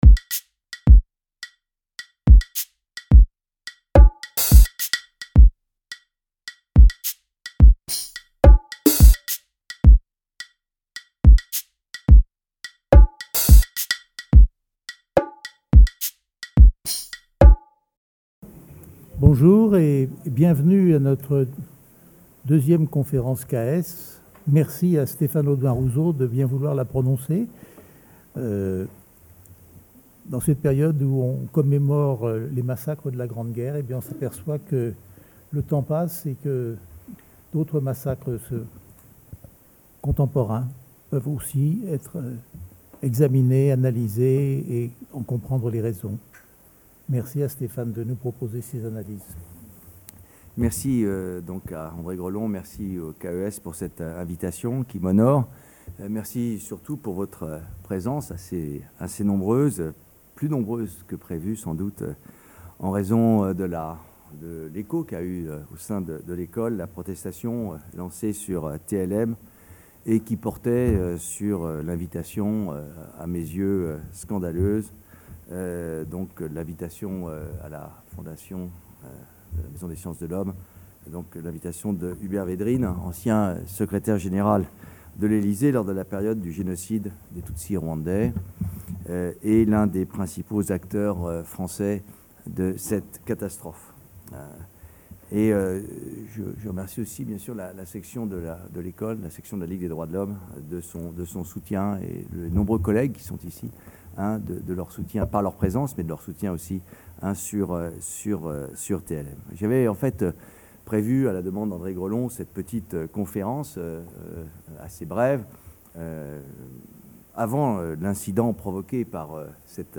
Dans le cadre des Conférences CAES, Stéphane Audoin-Rouzeau, directeur d’études, analysera les questions sur le génocide des Tutsi rwandais (avril-juillet 1994).